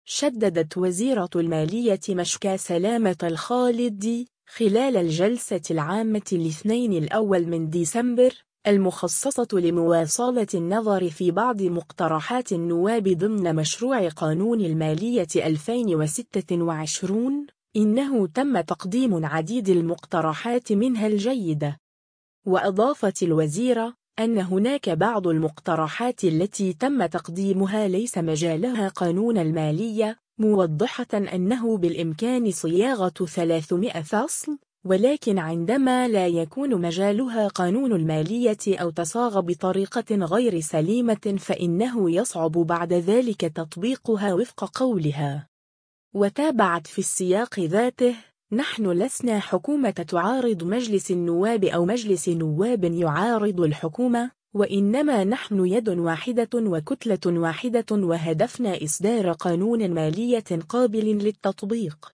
شدّدت وزيرة المالية مشكاة سلامة الخالدي، خلال الجلسة العامة الاثنين 1 ديسمبر، المخصصة لمواصلة النظر في بعض مقترحات النواب ضمن مشروع قانون المالية 2026، إنه تم تقديم عديد المقترحات منها الجيدة.